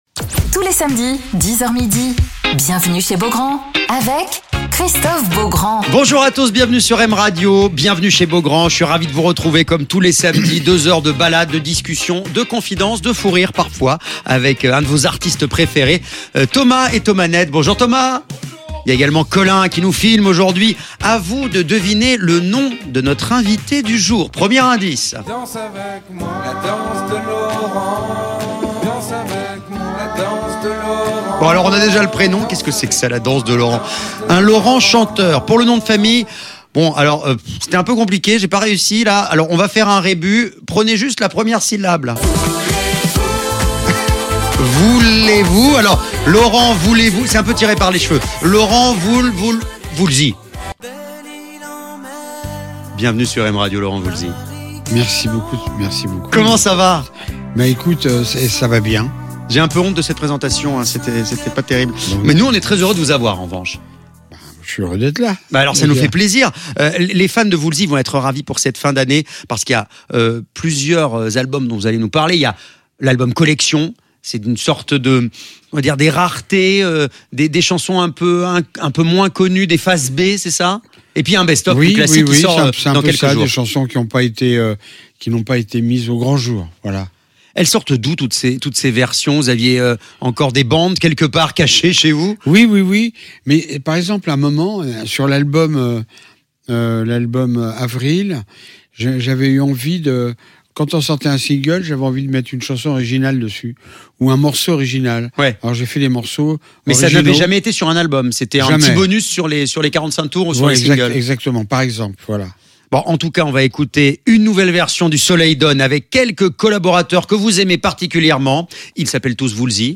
Alors qu'il sort son album "Collection", Laurent Voulzy est l'invité de Christophe Beaugrand sur M Radio